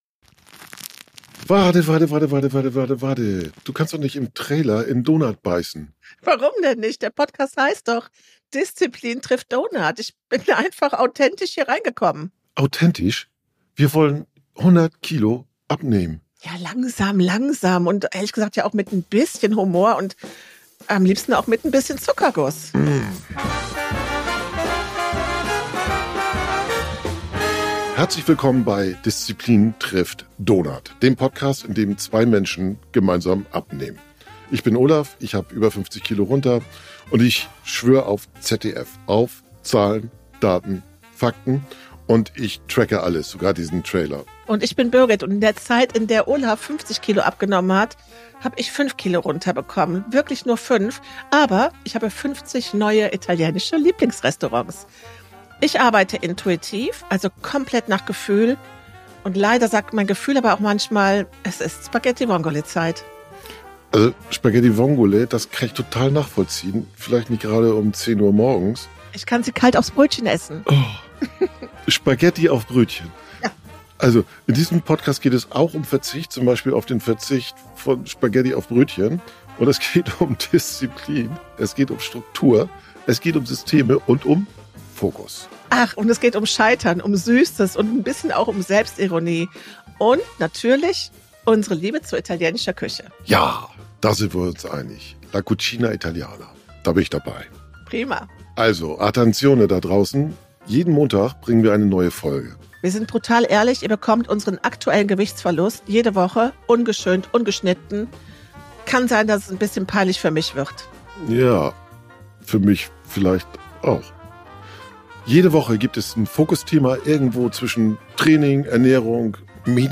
Sie streiten auf Augenhöhe.